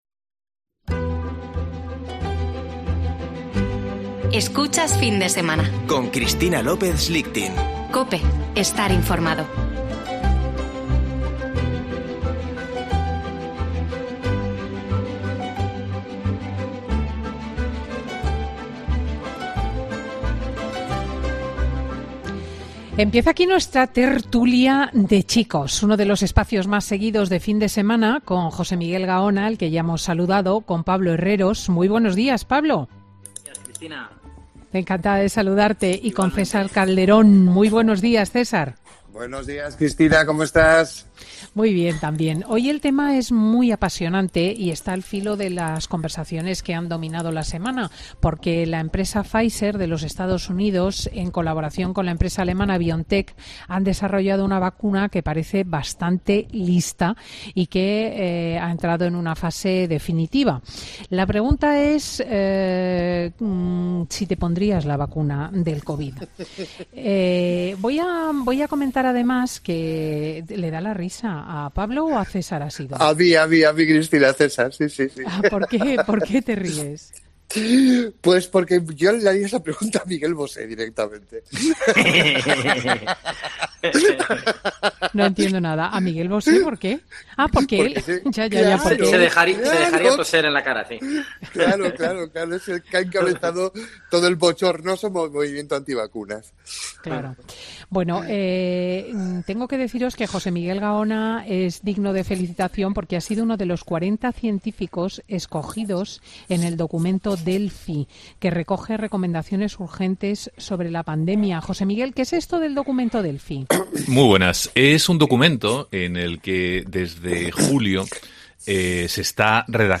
Tertulia de chicos: ¿te pondrías la vacuna para la covid19?